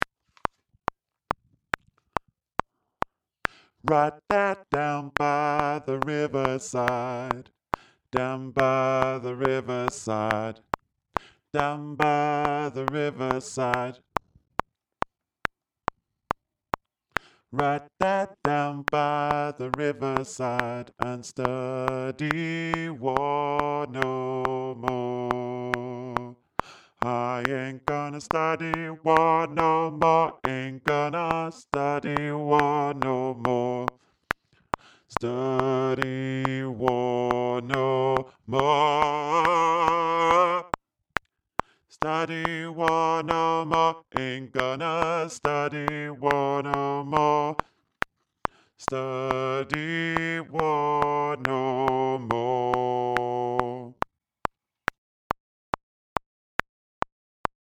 down-by-the-riverside-bass
down-by-the-riverside-bass.mp3